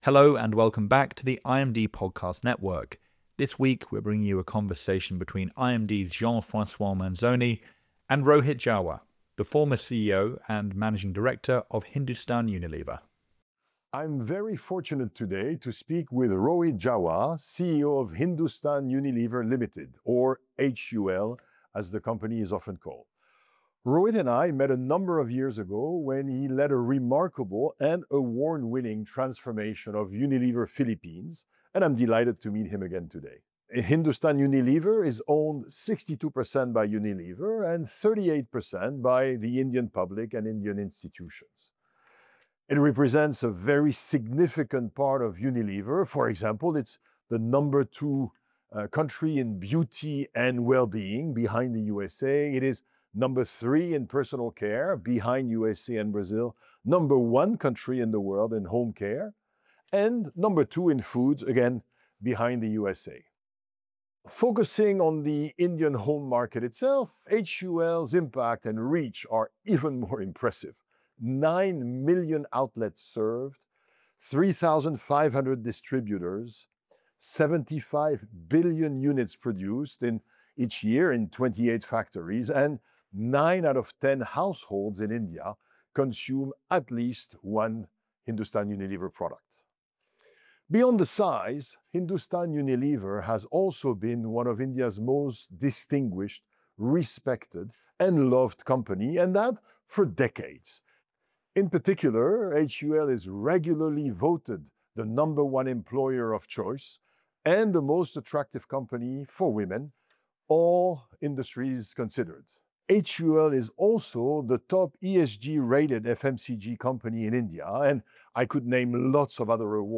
CEO interview